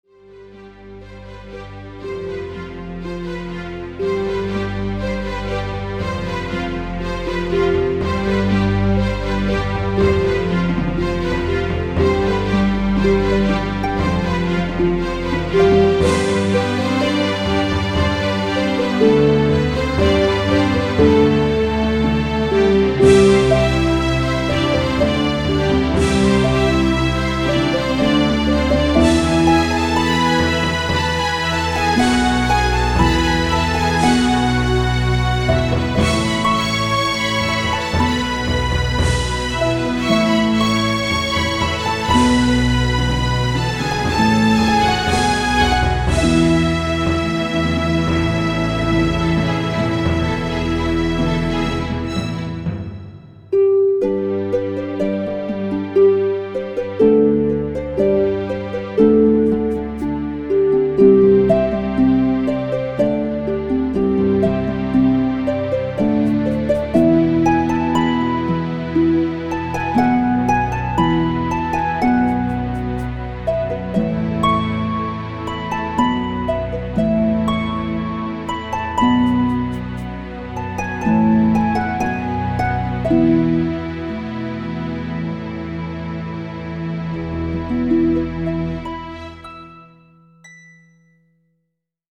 Mind stílusban, mind hangulatilag igazodnak az eredetiekhez.